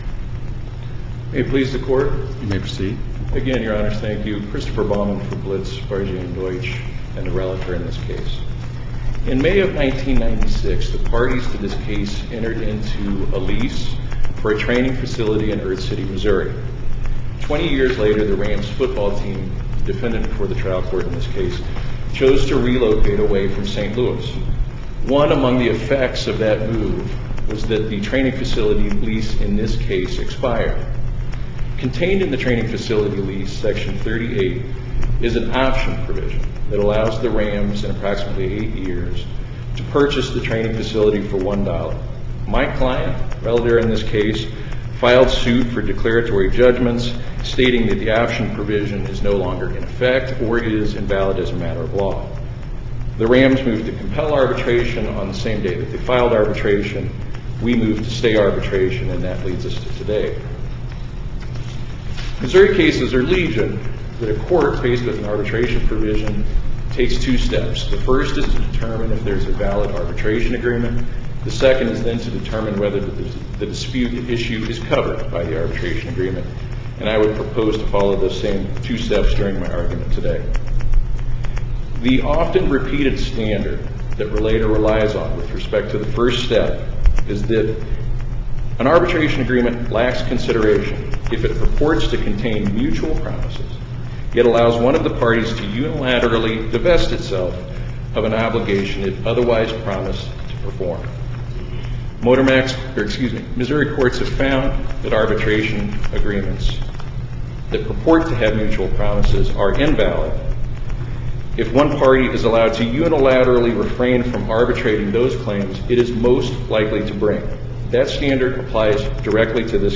MP3 audio file of arguments in SC96107